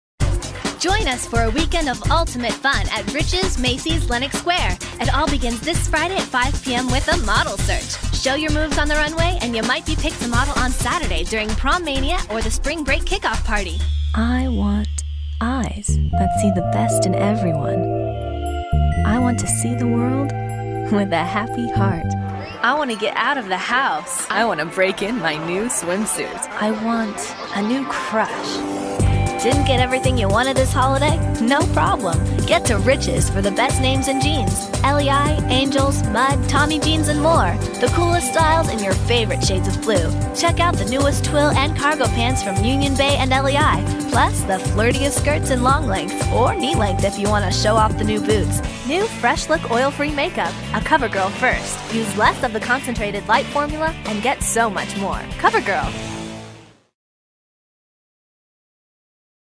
Female Voice Over, Dan Wachs Talent Agency.
Bright, young, edgy, real person.
Commercial